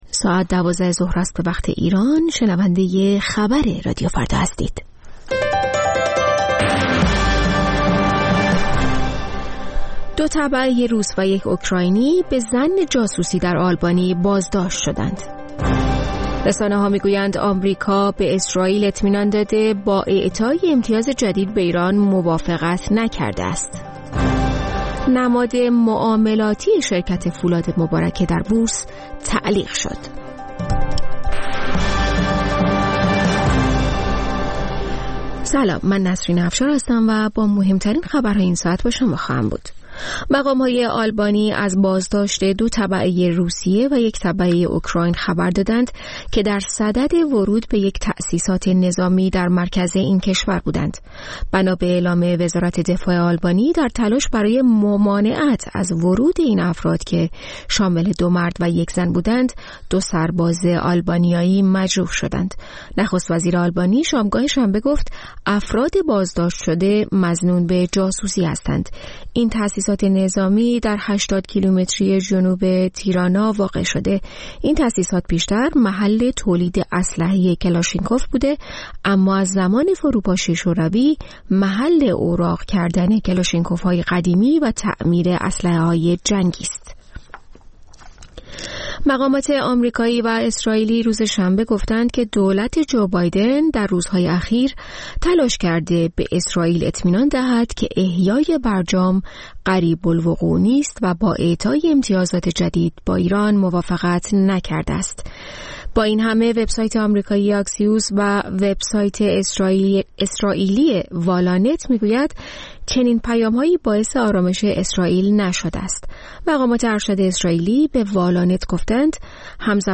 سرخط خبرها ۱۲:۰۰